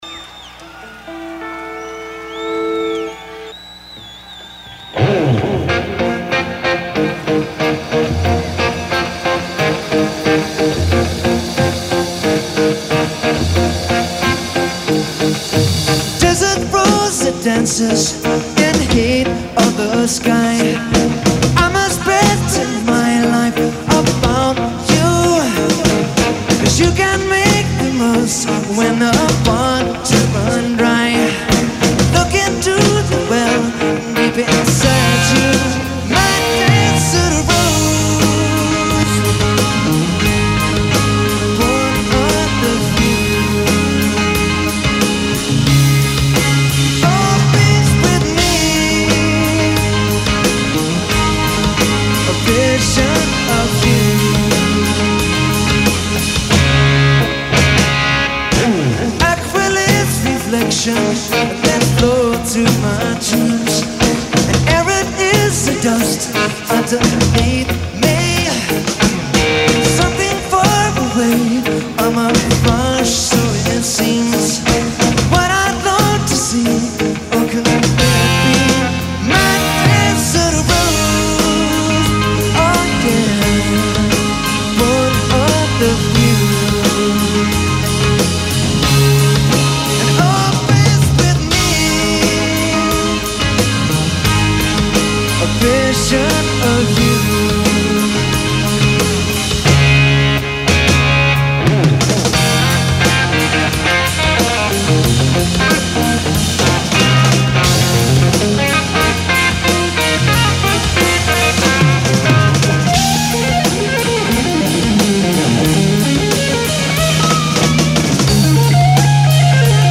absolutely no overdubs.